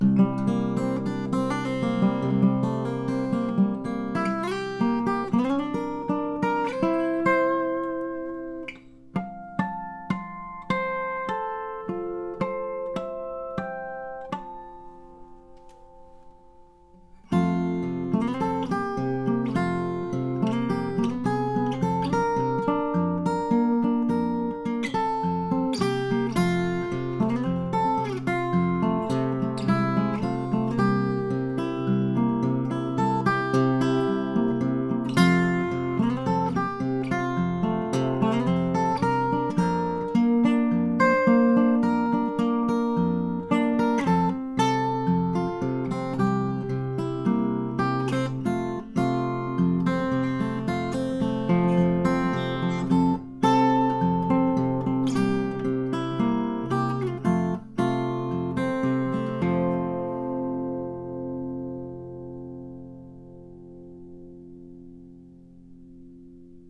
So here are the three clips I recorded in the video, with no EQ, no compression, no reverb, no processing of any kind except to match volume levels and trim ends.
Well my favorites, at least for acoustic guitar given these recordings, are L then J then K, can you provide the key?
My choice J then L and K Find J sharper , K more coloured with more mids.